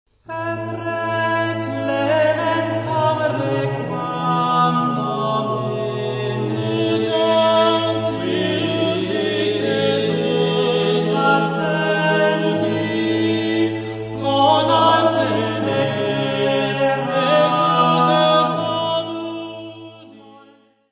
Área:  Música Clássica